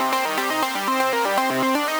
SaS_Arp05_120-C.wav